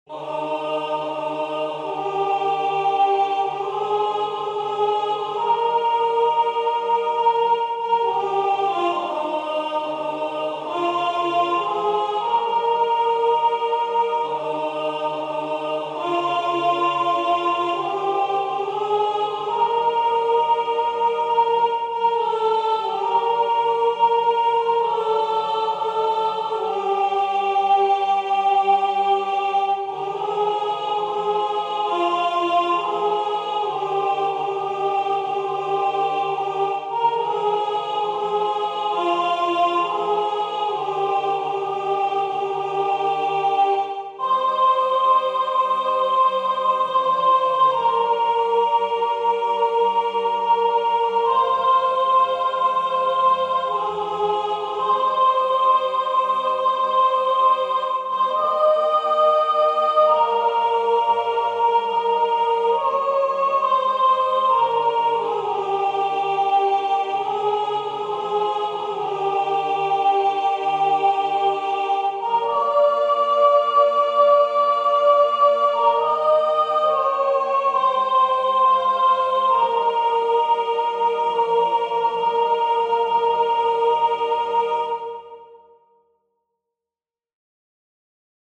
O_Holy_Night_alto.mp3